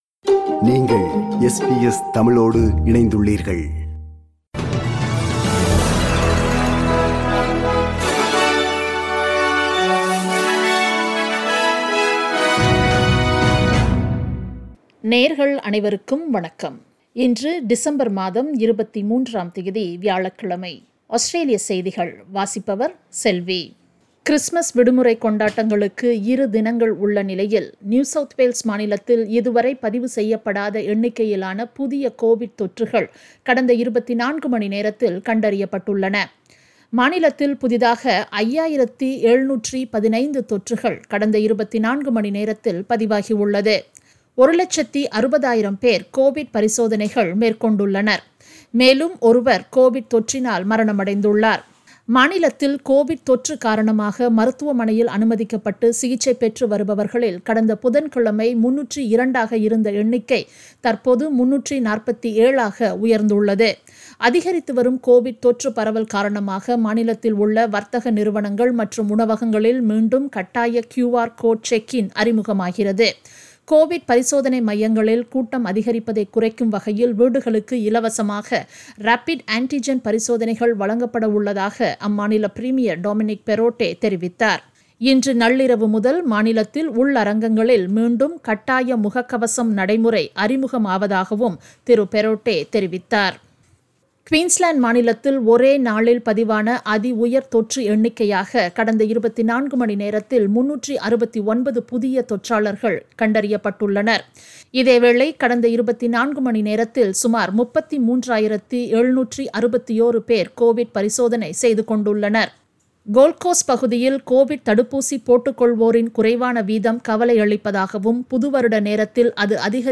Australian news bulletin for Thursday 23 December 2021.
australian_news_23_dec_-_thursday.mp3